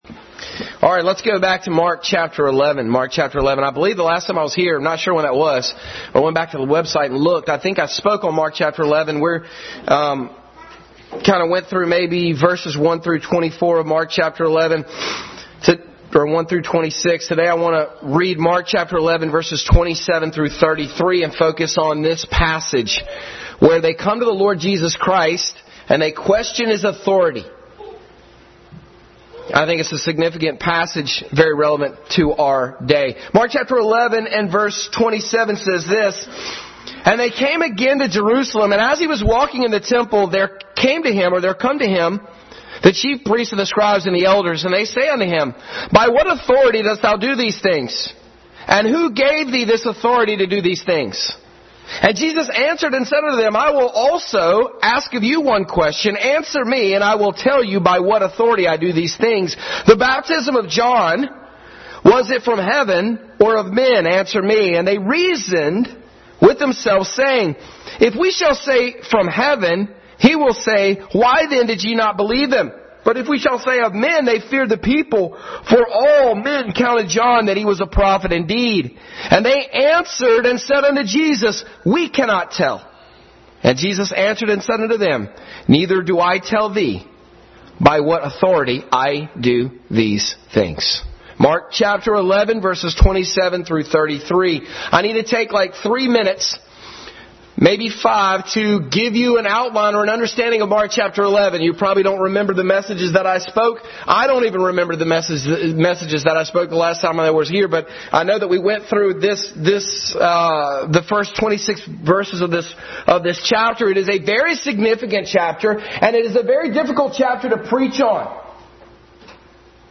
Family Bible Hour Message